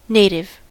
native: Wikimedia Commons US English Pronunciations
En-us-native.WAV